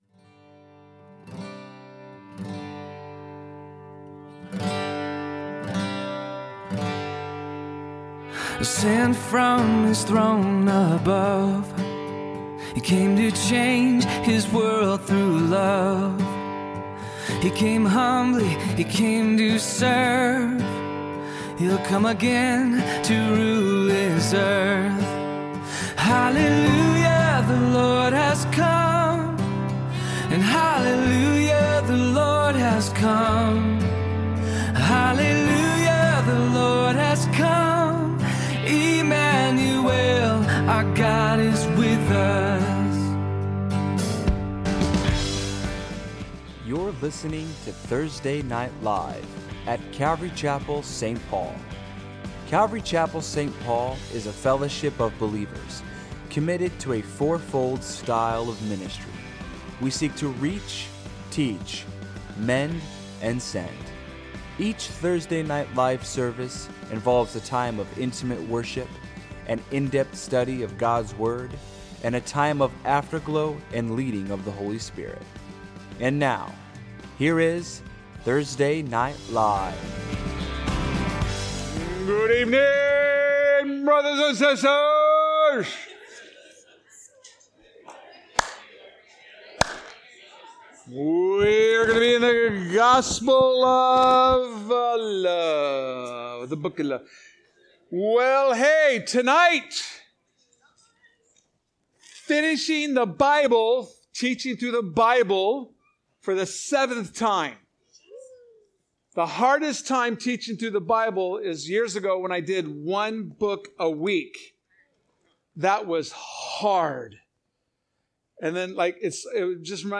A message from the series "3000 Series."